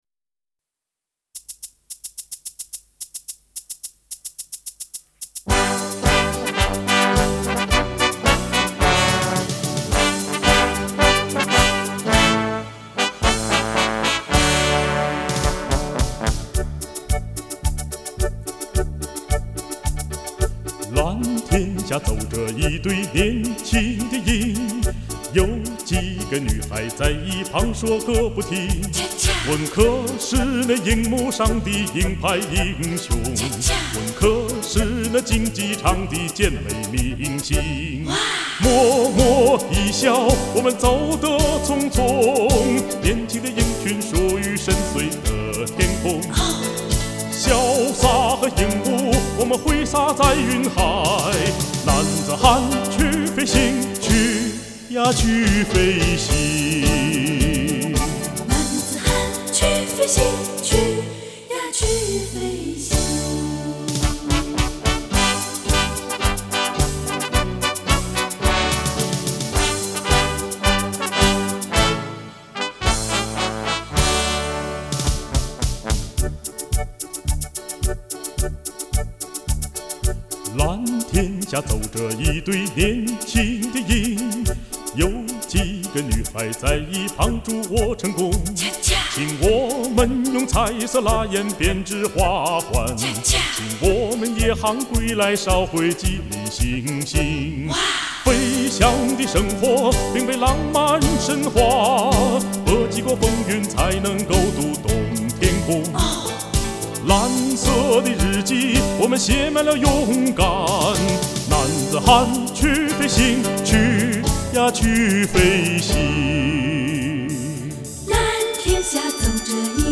质朴的情感，奔放的激情，难忘的旋律。
男声合唱